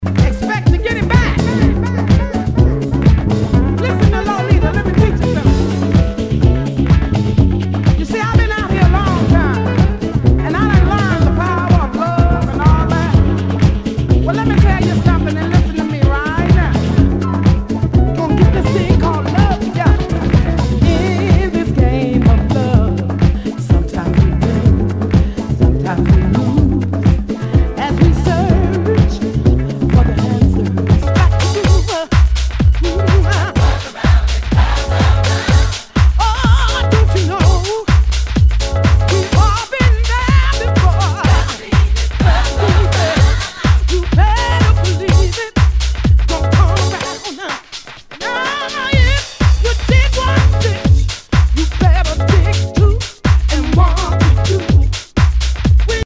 HOUSE/TECHNO/ELECTRO
ナイス！ヴォーカル・ハウス！
全体にチリノイズが入ります